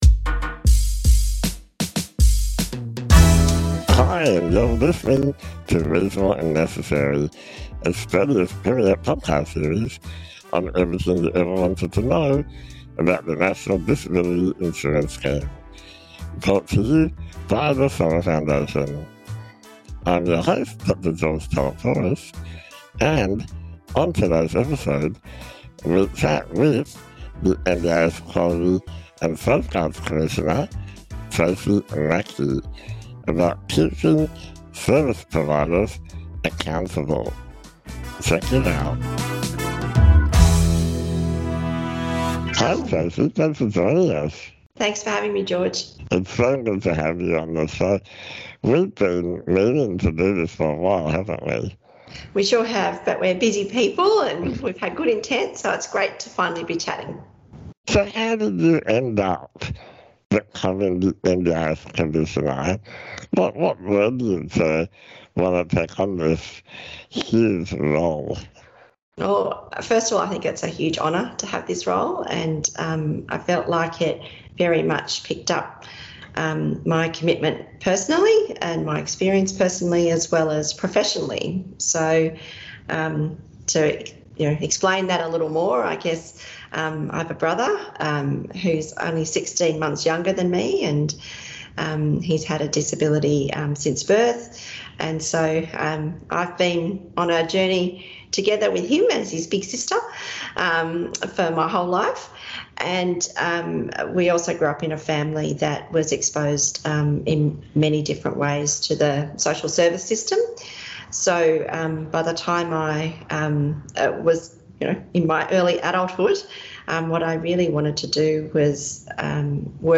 When NDIS services turn bad - Interview with NDIS Commissioner Tracy Mackey